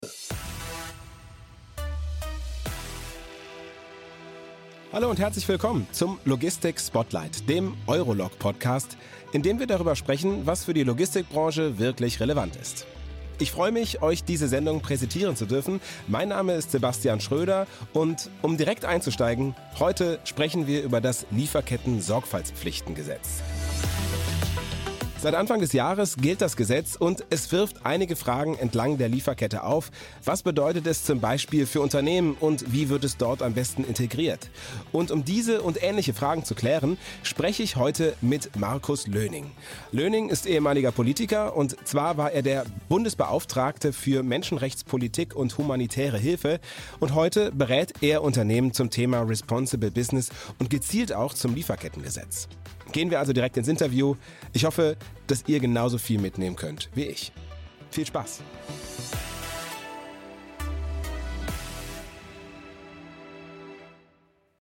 Interview-Podcast
Intro für Corporate-Podcast
Meine stimmcharakteristik ist warm, klar und nahbar.